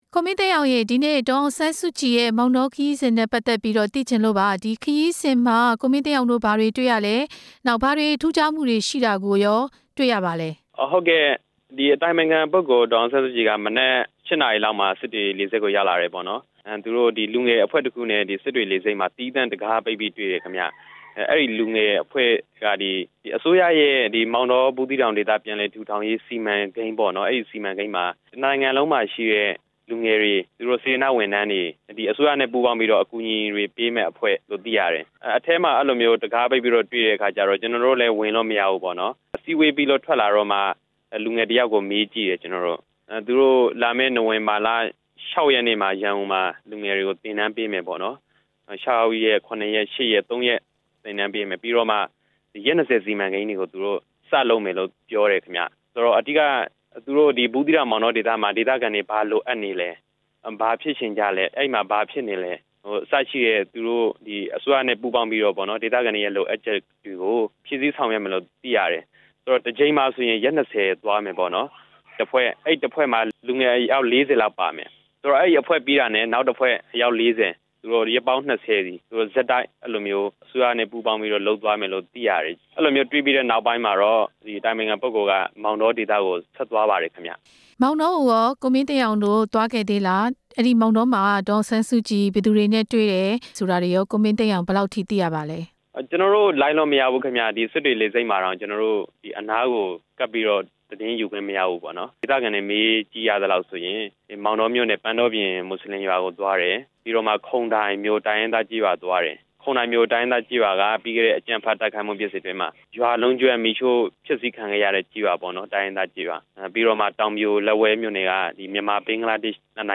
ဒေါ်အောင်ဆန်းစုကြည်ရဲ့ မောင်တောခရီးစဉ် မေးမြန်းချက်